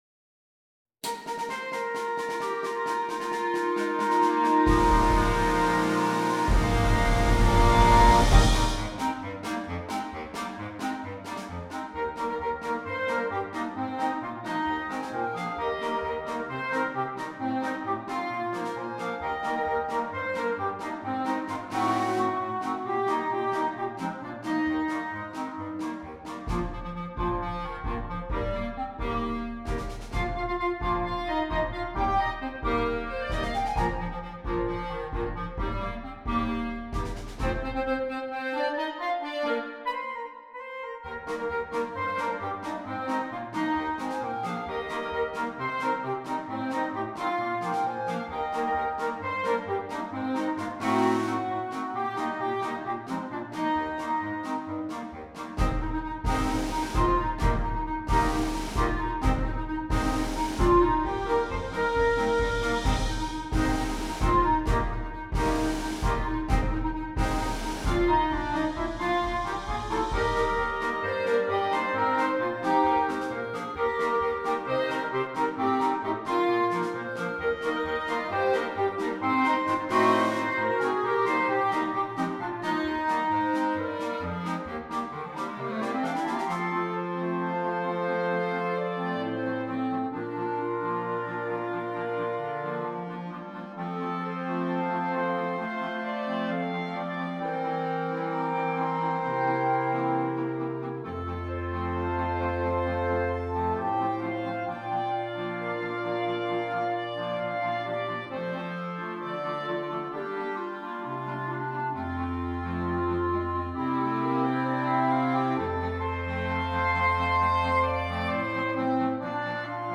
13 Clarinets, Bass Clarinet